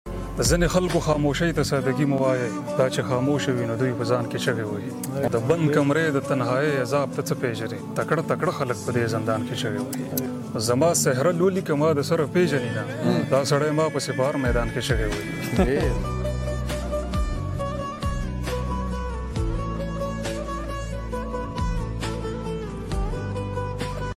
All Pakistan Declamation contest at sound effects free download
All Pakistan Declamation contest at RMI peshawer